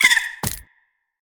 Sfx_creature_trivalve_hide_01.ogg